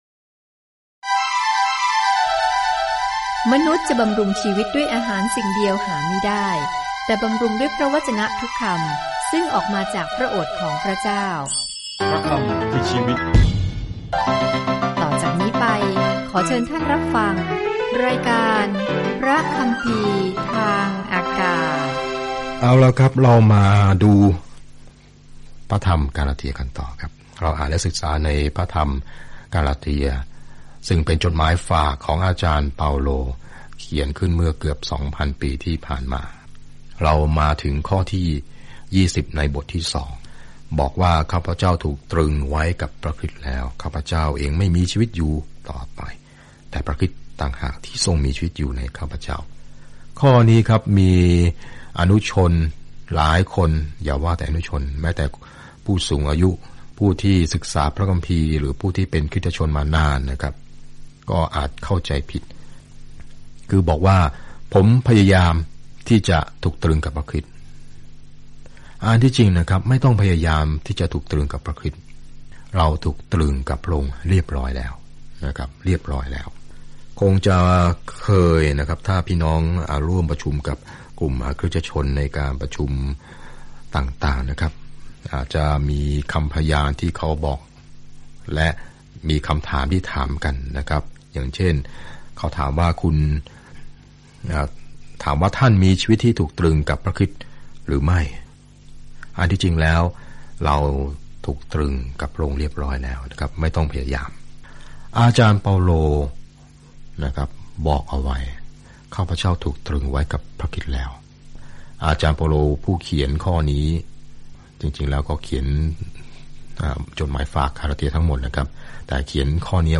เดินทางทุกวันผ่านกาลาเทียในขณะที่คุณฟังการศึกษาด้วยเสียงและอ่านข้อที่เลือกจากพระวจนะของพระเจ้า